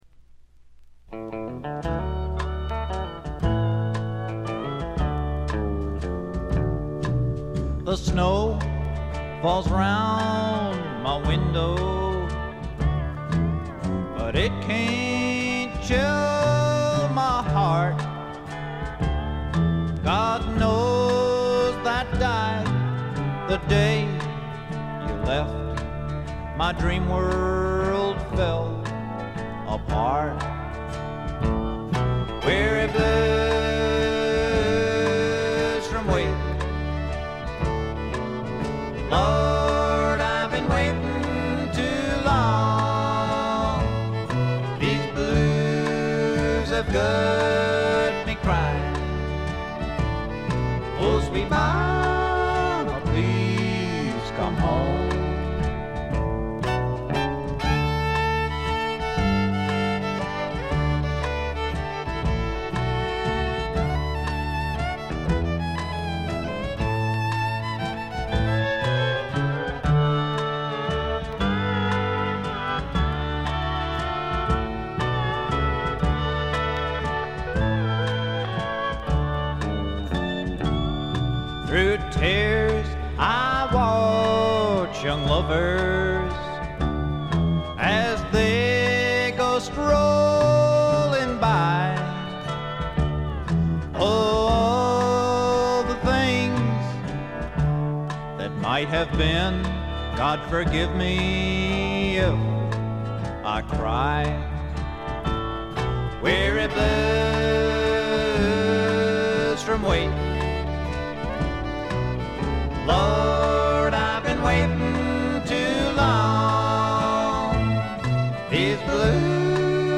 これ以外は軽微なチリプチ少々で良好に鑑賞できると思います。
試聴曲は現品からの取り込み音源です。